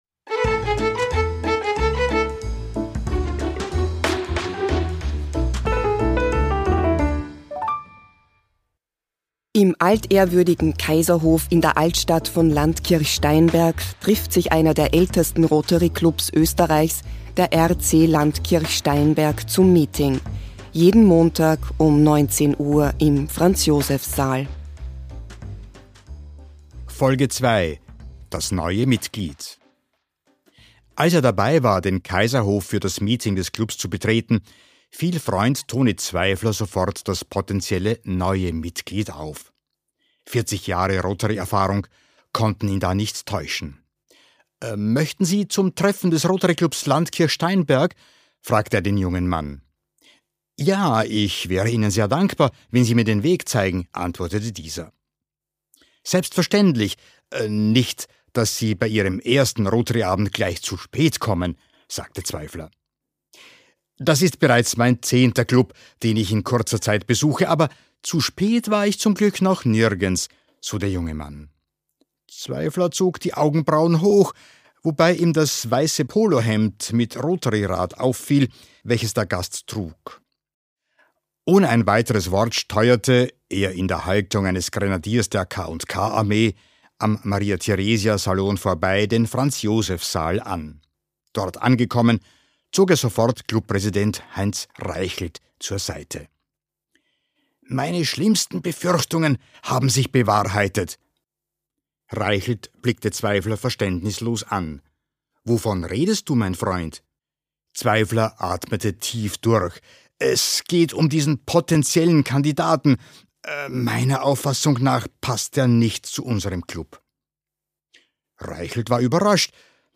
Voices: Full cast
Narrator: First Person
Genres: Comedy
Soundscape: Voices only